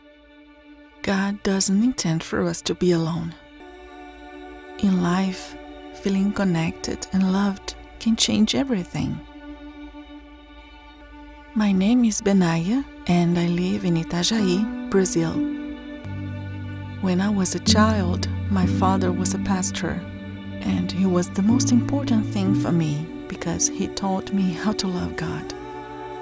Female
30s, 40s, 50s, 60s
Microphone: Shure KSM 27
Audio equipment: sound proof recording both